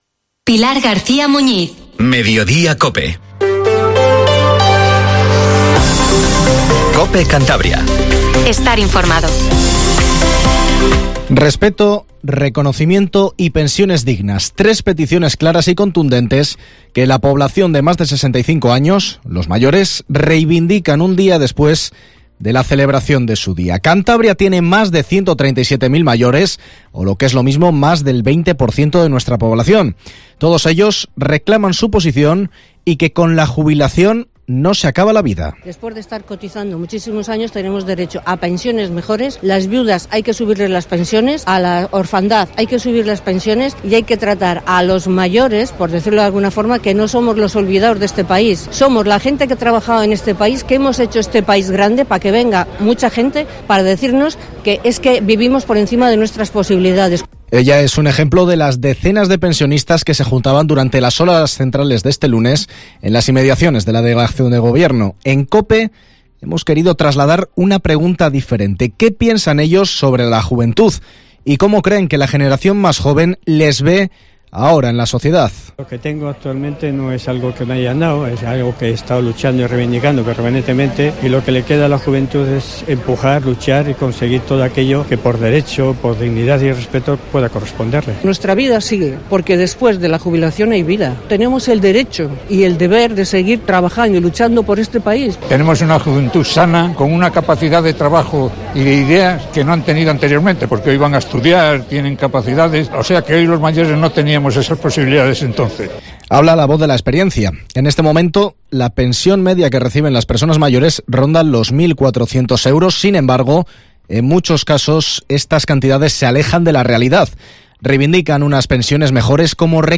Informativo MEDIODIA en COPE CANTABRIA 14:48